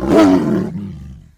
tiger
attack3.wav